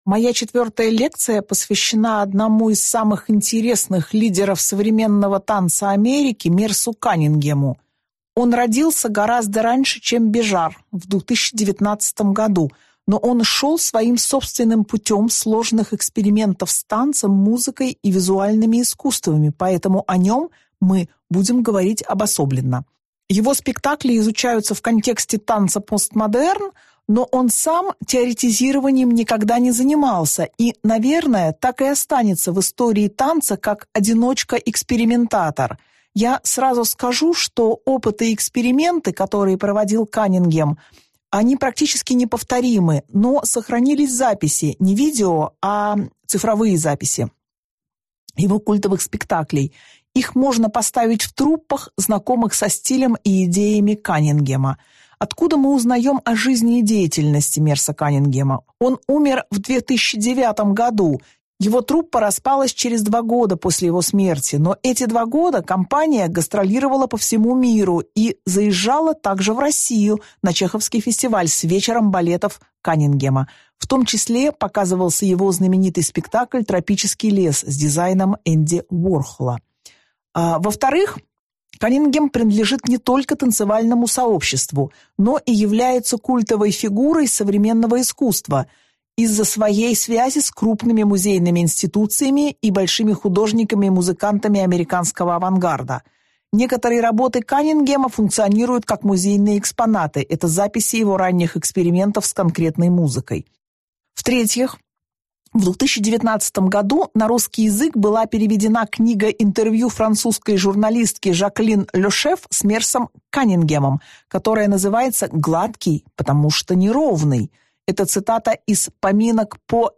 Аудиокнига Мерс Каннингем, или новая эра contemporary dance | Библиотека аудиокниг